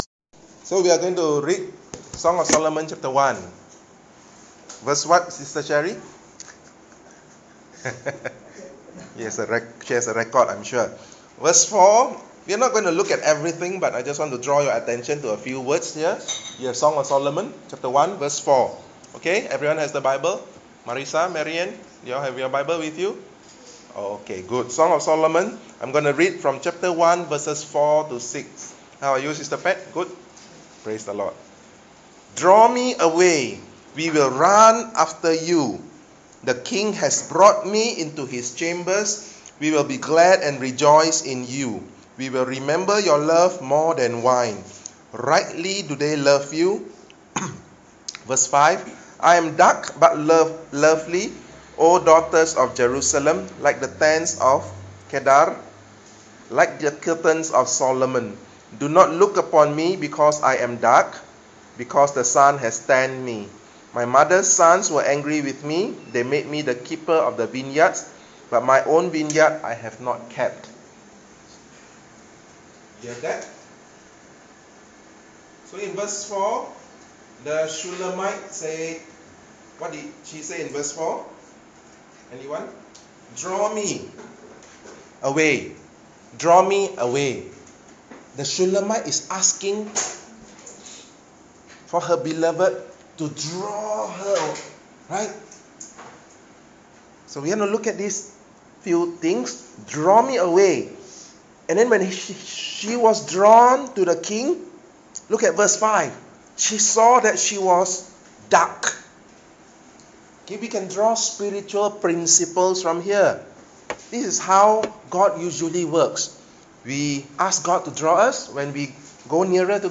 All Sermons